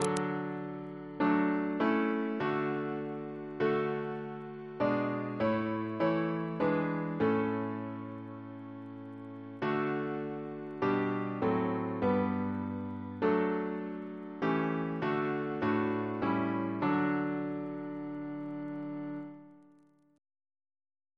Double chant in D Composer: John Randall (1717-1799), Professor of Music, Cambridge Reference psalters: ACB: 282; ACP: 69; H1940: 626 657; OCB: 100; PP/SNCB: 81; RSCM: 112